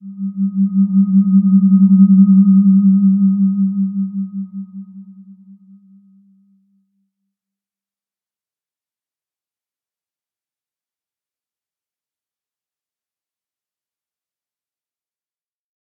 Slow-Distant-Chime-G3-p.wav